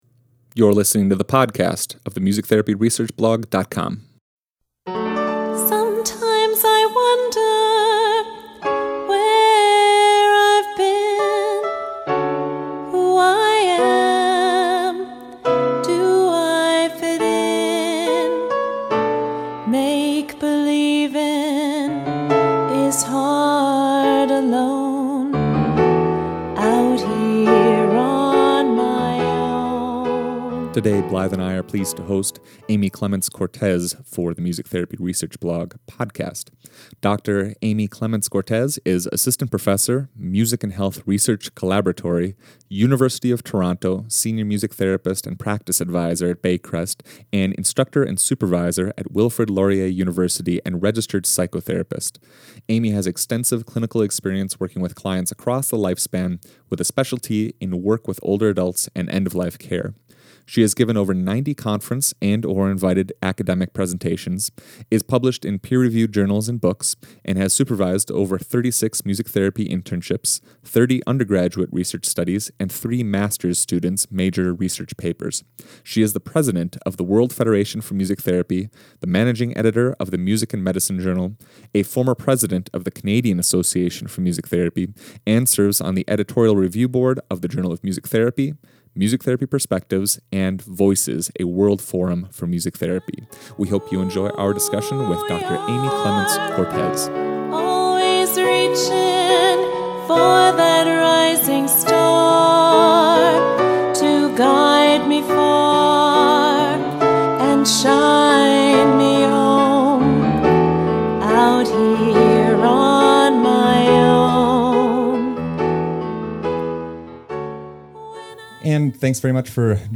Five voices on one podcast!